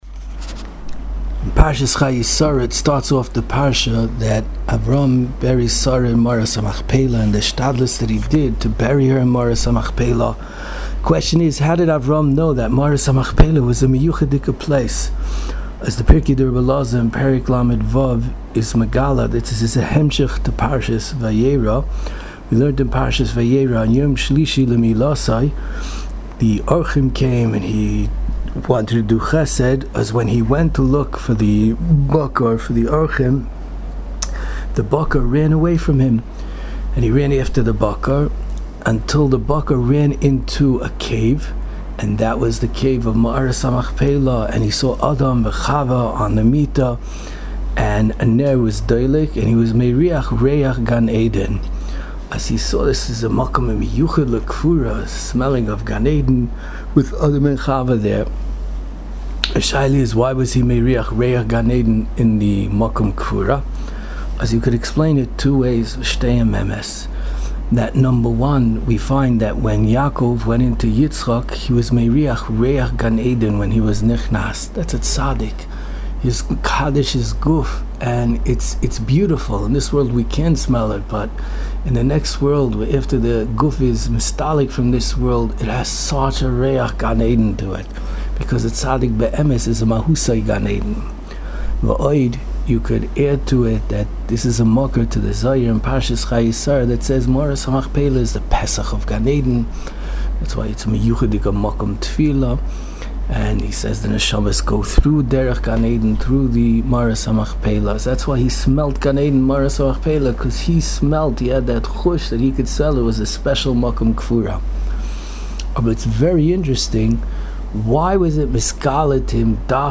Divrei Torah, Shiurim and halacha on Parshas Chayei Sara from the past and present Rebbeim of Yeshivas Mir Yerushalayim.